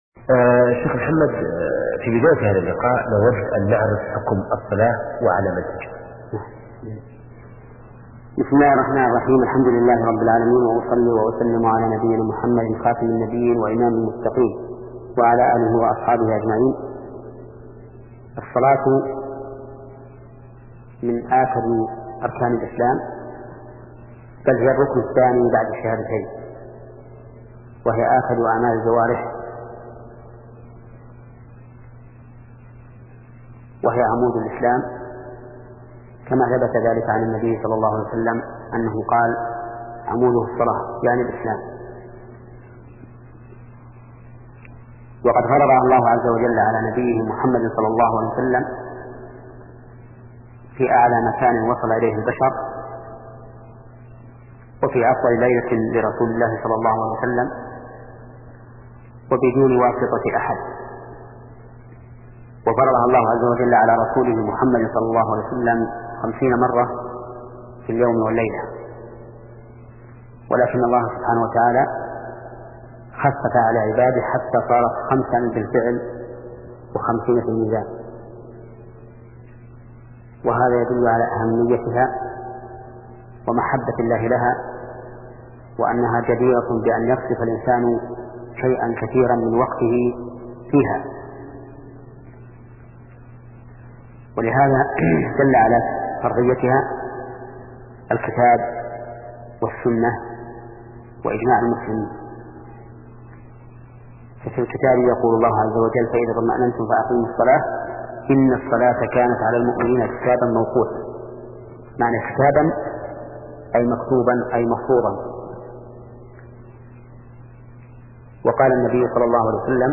شبكة المعرفة الإسلامية | الدروس | سلسلة فقه العبادات 5 |محمد بن صالح العثيمين